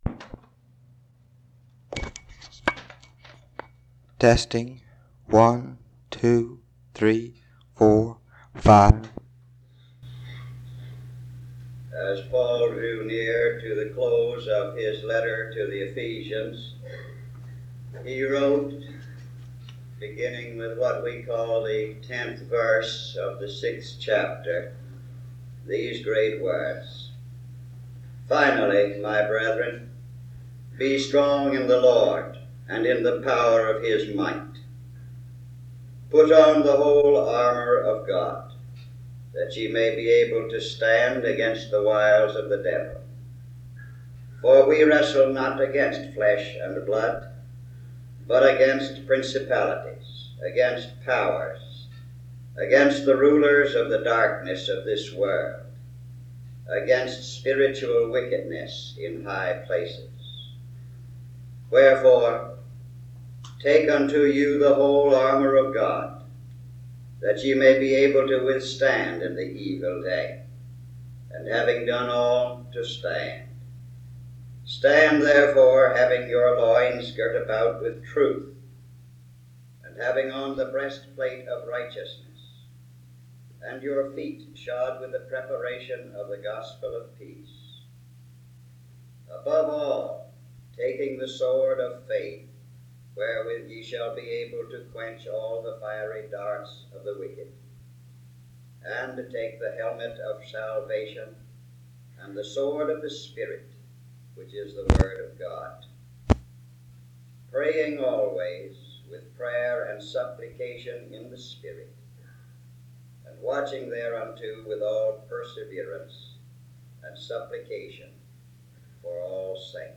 Dr. Helmut Richard Niebuhr, a Christian theologian, Professor at Yale Divinity School, and author of Christ and Culture, speaks on the impact Protestantism had at that time, and how they influence and shape culture.
In Collection: SEBTS Chapel and Special Event Recordings SEBTS Chapel and Special Event Recordings - 1950s Miniaturansicht Titel Hochladedatum Sichtbarkeit Aktionen SEBTS_Event_H_Richard_Niebuhr_1958-01-23.wav 2026-02-12 Herunterladen